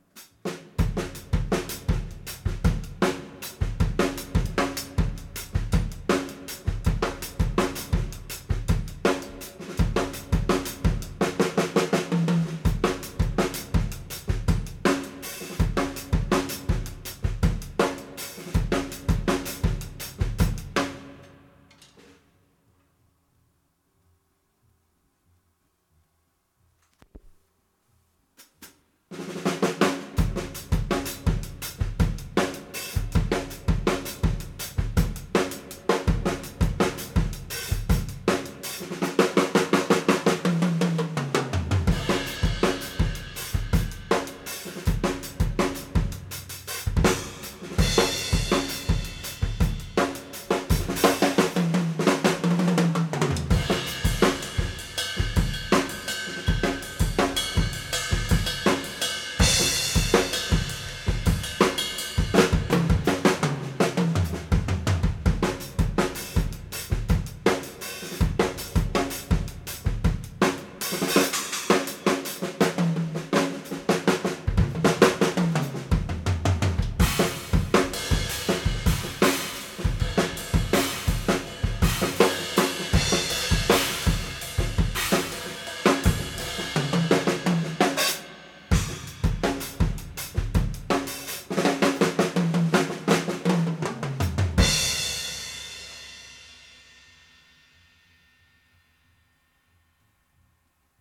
this rough test of my Ampex MM-1000 tracking some drums...its on a sidechain of the mix buss in this application.
In this version of the test I'm messing with muting and EQ settings as well as reverb sourcing, so there are some instances where you can hear the mix without reverb as well as reverb isolated.
That's a nice drum sound.
Studio Projects B3s haphazardly hanging overhead in cardioid, and an Audix D-6 inside the kick.
Mic pres were the onboard pres on my early 80's Tascam prototype mixer, no dynamics processing, some careful EQ notching on the kick and some mild mid and HF boosting on the overheads.
Tape was Ampex 499 but the Ampex machine hasn't been calibrated in I don't know how many years/decades and surely its not setup for 499.
Tape just sounds good.
The kick sounds huge in the monitors.
But the reverb does a nice job.
with_reverb_and_eq.mp3